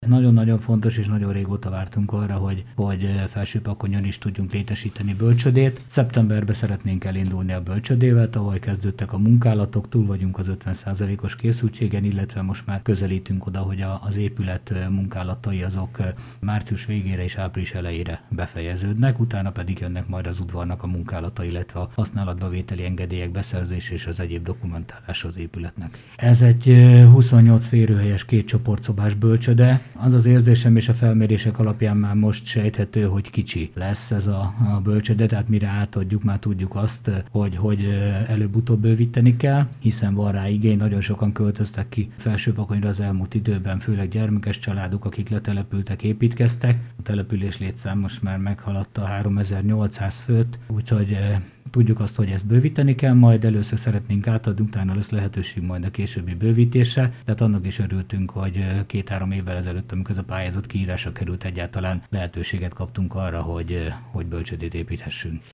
Jelenleg csak óvodai szolgáltatás van Felsőpakonyon, így a szeptemberben induló bölcsőde jelentős előrelépés lesz a településnek. Nagy János polgármestert hallják.